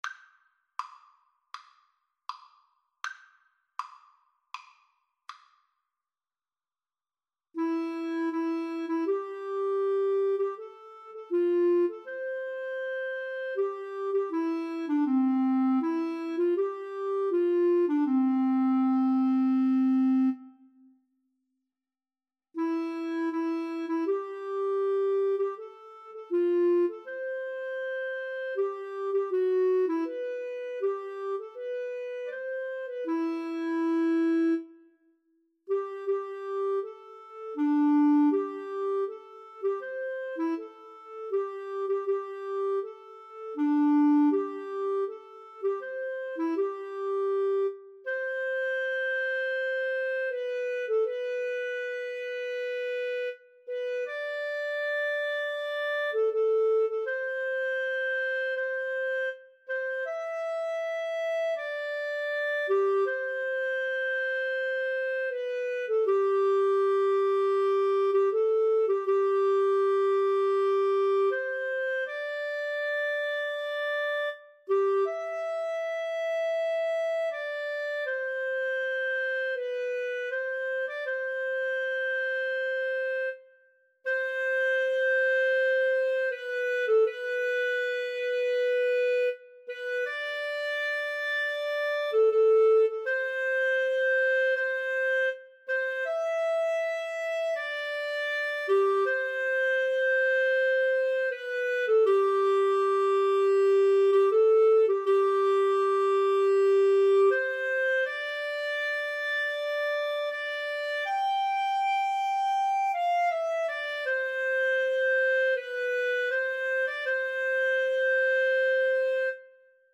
Free Sheet music for Clarinet-Viola Duet
C major (Sounding Pitch) (View more C major Music for Clarinet-Viola Duet )
Andante